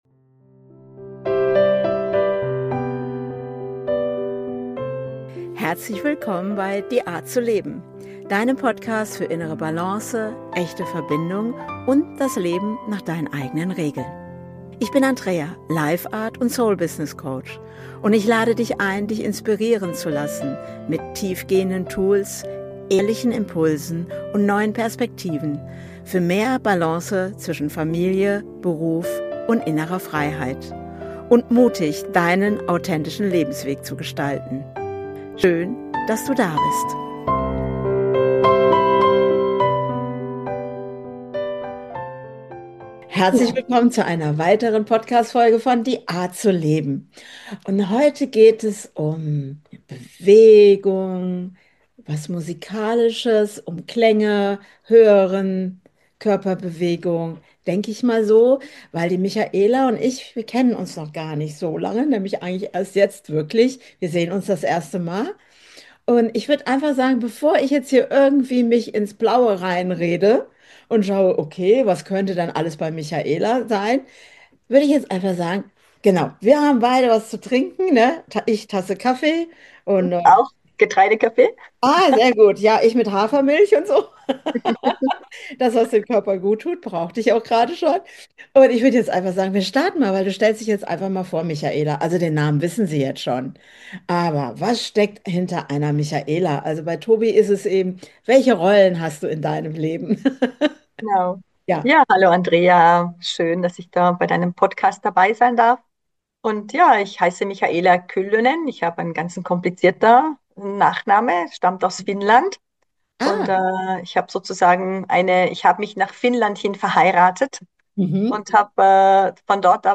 #121 Der Klang deines Lebensrythmus - Interview